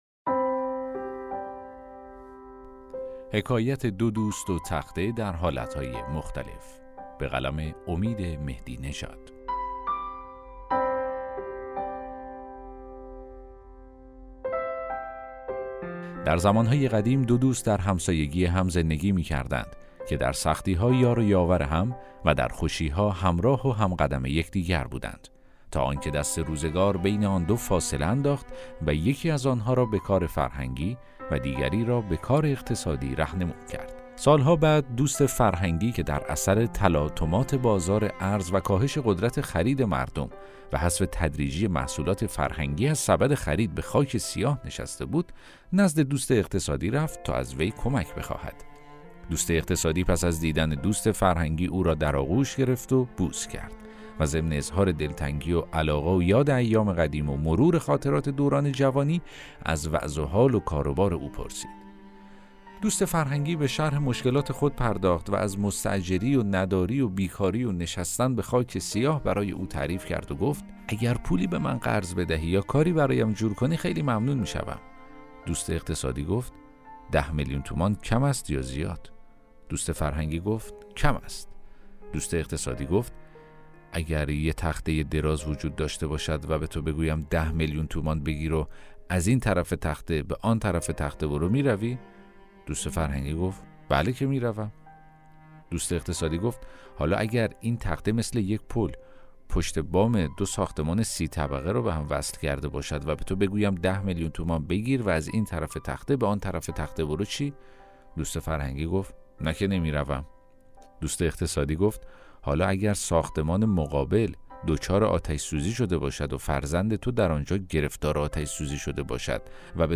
داستان صوتی: حکایت ۲ دوست و تخته در حالت‌های مختلف